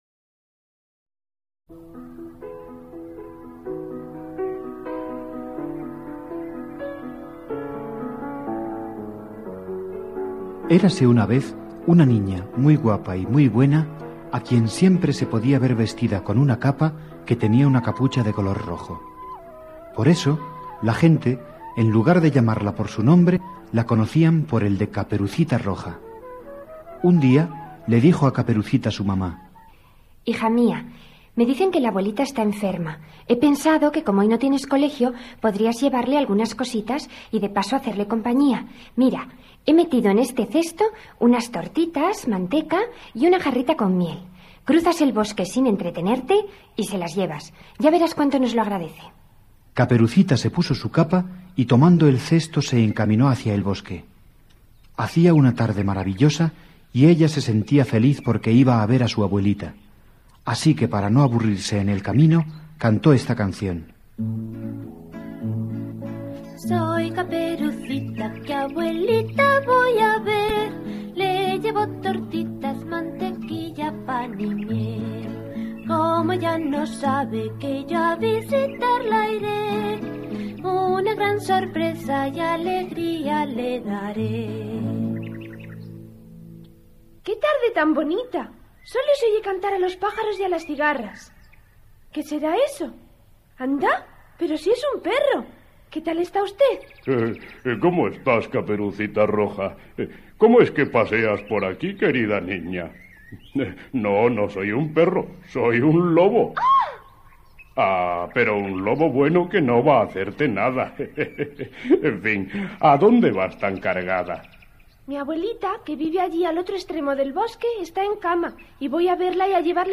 Cuentos Infantiles - Caperucita Roja.mp3